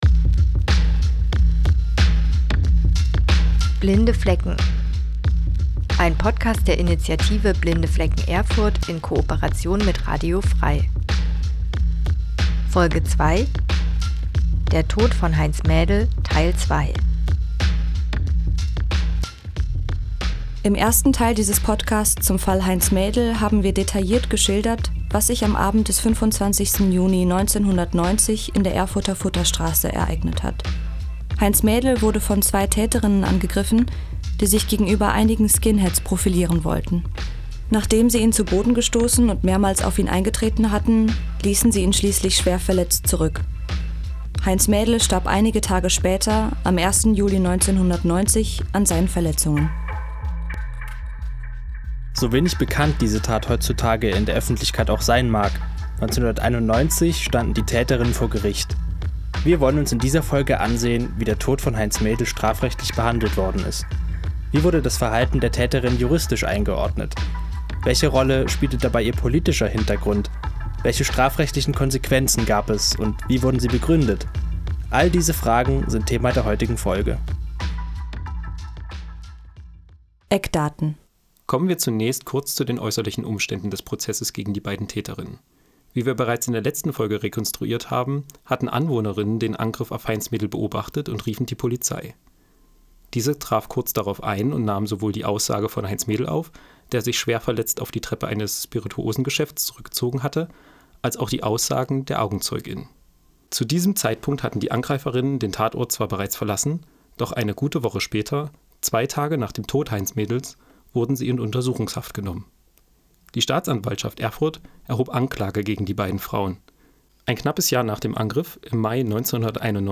Text, Stimme, Musik: Blinde Flecken